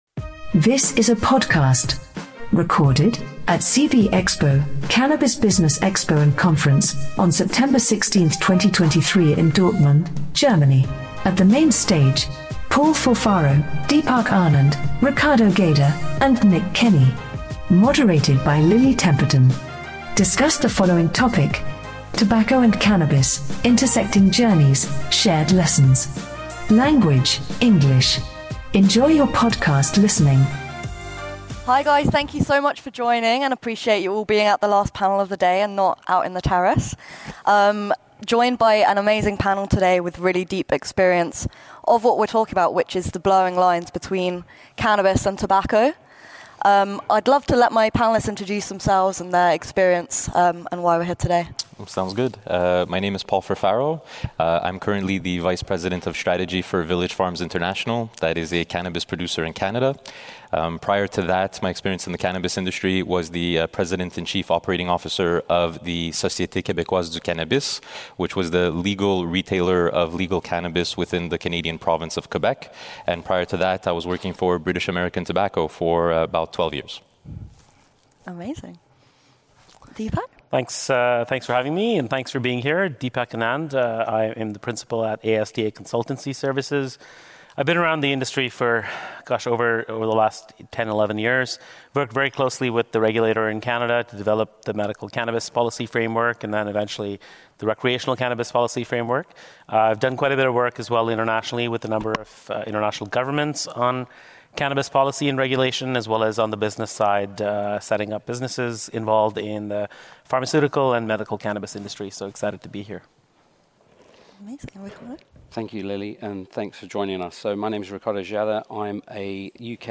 What are some challenges that big tobacco companies face when entering or considering the cannabis industry? This panel will delve into the ethical considerations and historical backdrop. Drawing comparisons in trade, production, and taxation across these industries, the conversation will spotlight strategic alliances, product innovation partnerships, and shifts in consumption techniques.